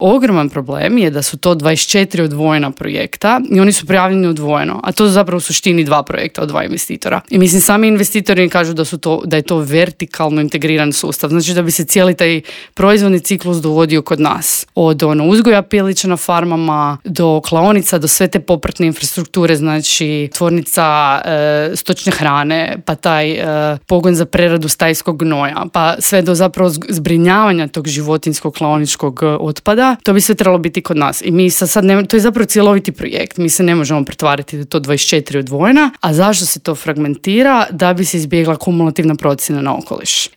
Intervjuu Media servisa